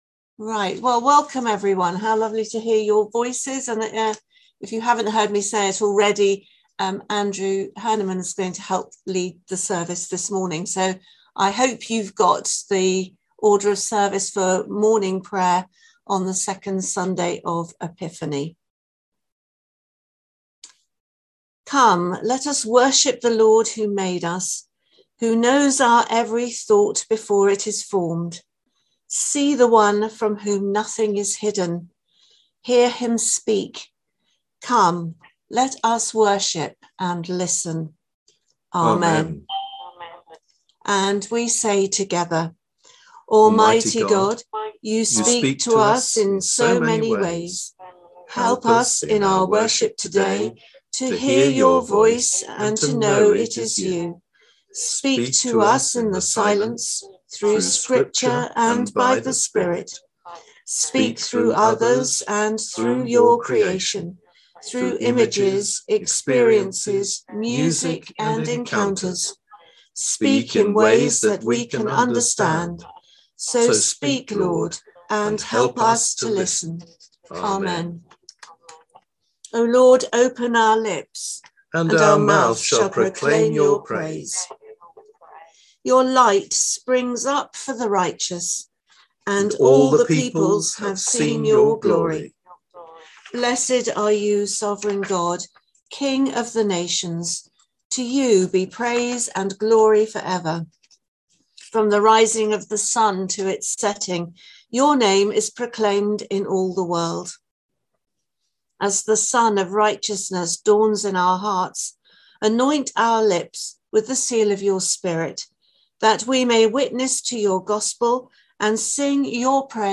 The audio from the Zoom / Conference Call service on Advent Sunday 28/11/2021.